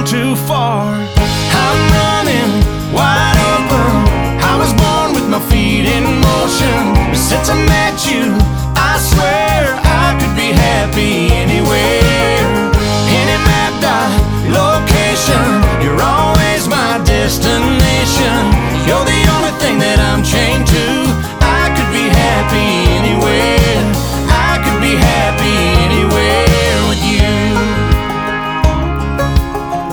• Country
upbeat country duet